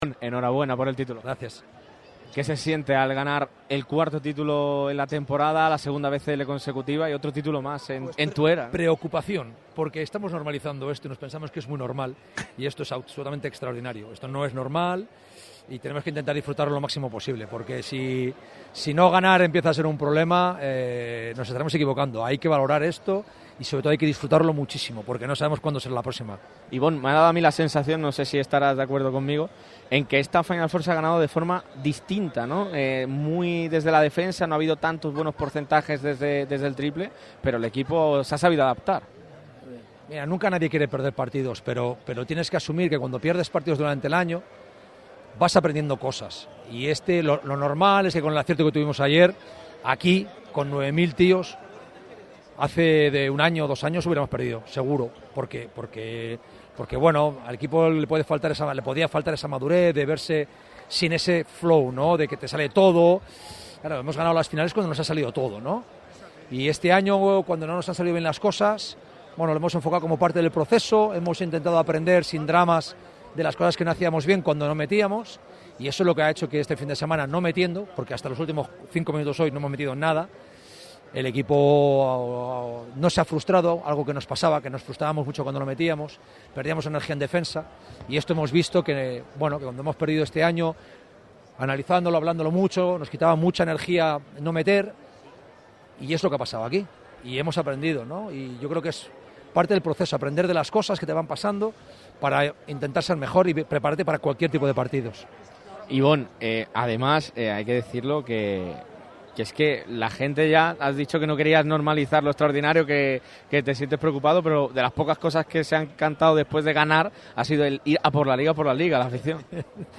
Las reacciones de los campeones de la BCL sobre el parqué del Sunel Arena.
IBON NAVARRO, ENTRENADOR DEL UNICAJA – PIE DE PISTA